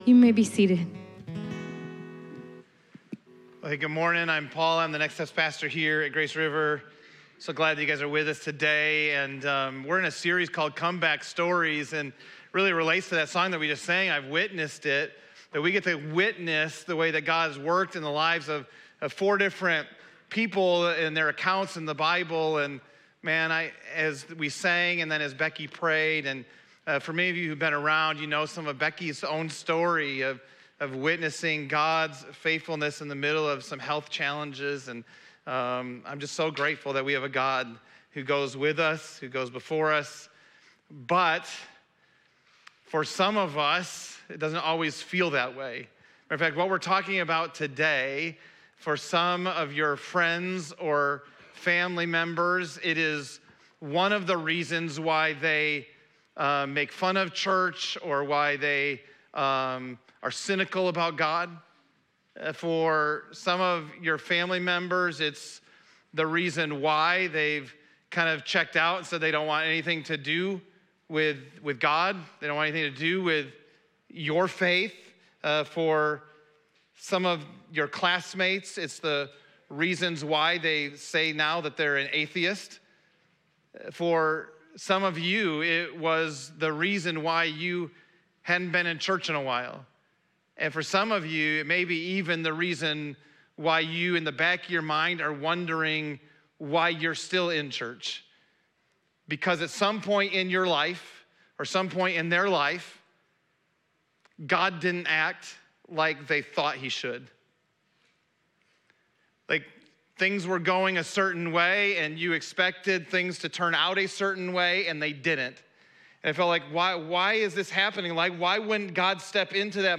Comeback Stories - Week 2 | Full Sermon | Grace River Church